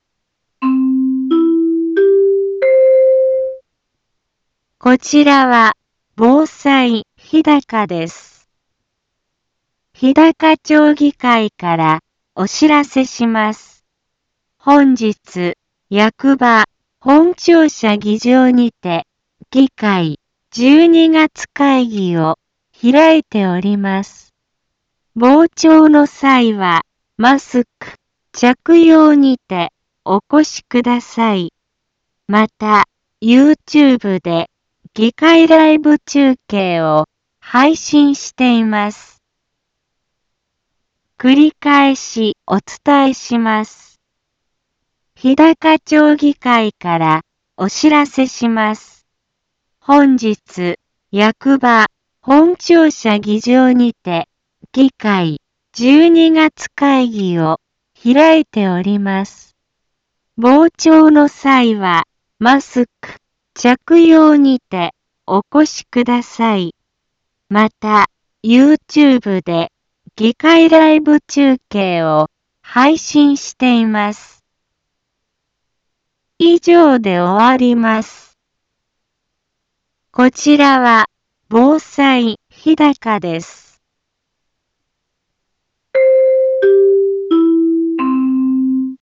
BO-SAI navi Back Home 一般放送情報 音声放送 再生 一般放送情報 登録日時：2022-12-14 10:03:10 タイトル：日高町12月会議のお知らせ インフォメーション：こちらは防災日高です。